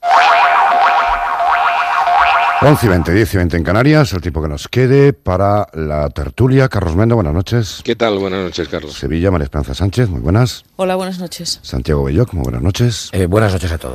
Presentació dels integrants de la tertúlia
Informatiu